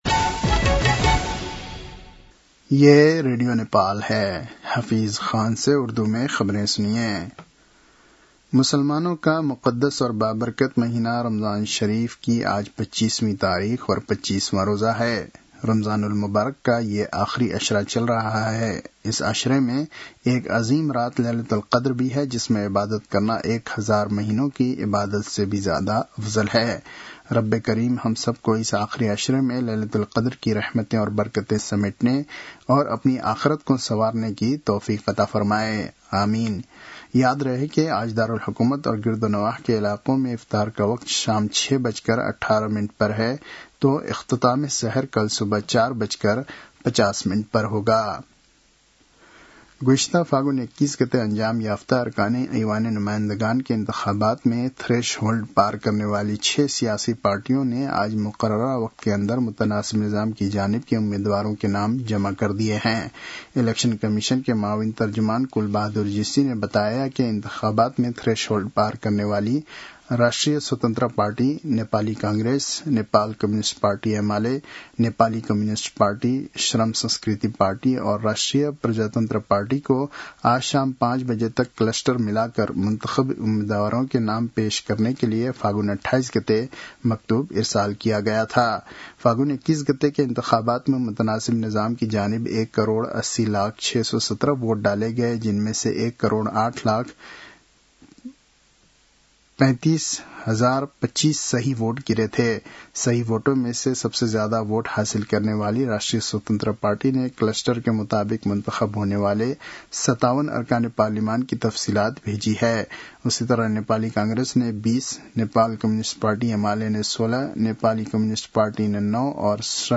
उर्दु भाषामा समाचार : १ चैत , २०८२
Urdu-news-12-01.mp3